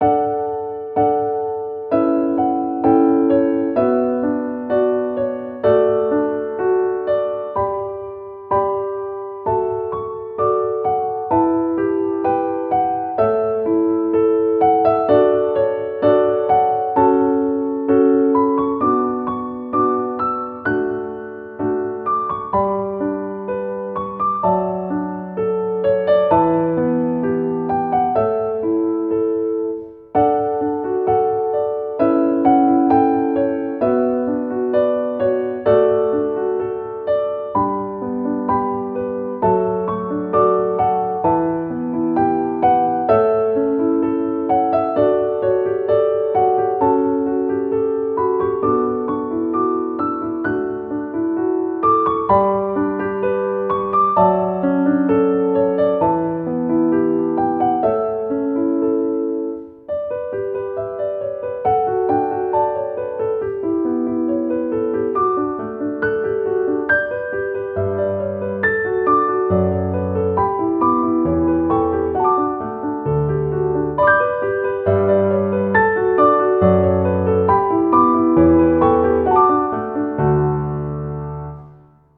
ogg(R) - 癒し まったり 安らぎ
優しいゆったりピアノ。